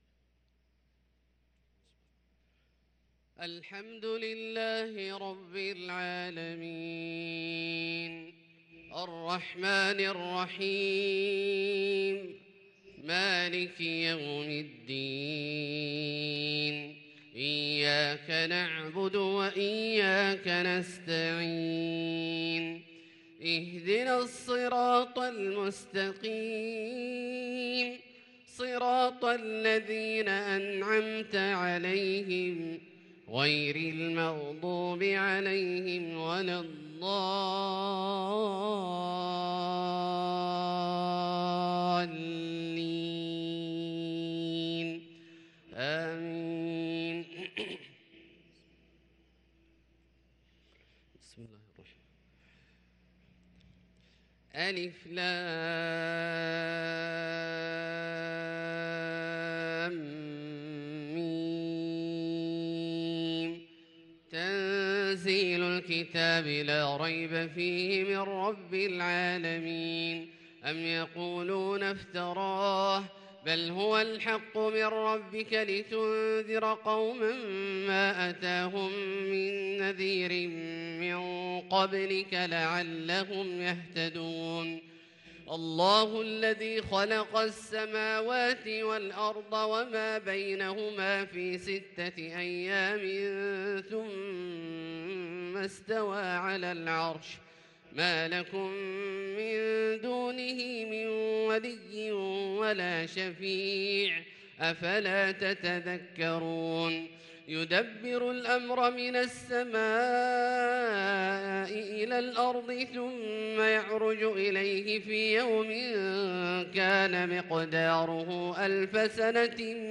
صلاة الفجر للقارئ عبدالله الجهني 6 صفر 1444 هـ